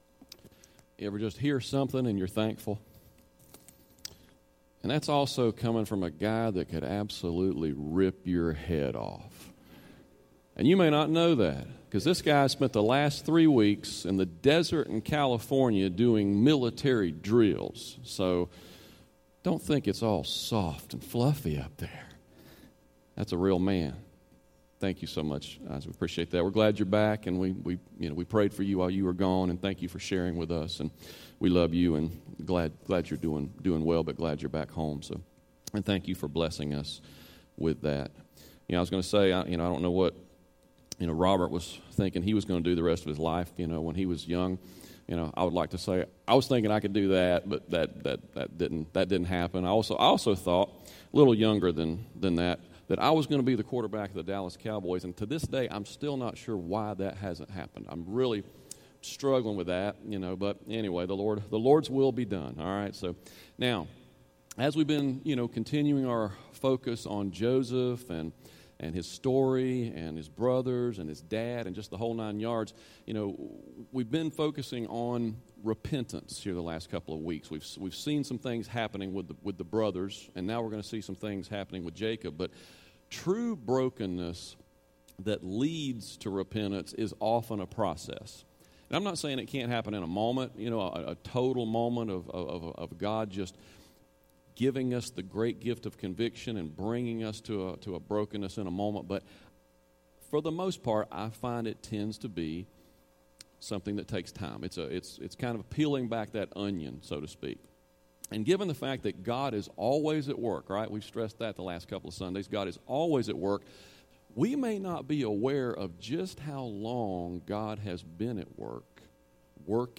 Sermons | Hampton Heights Baptist Church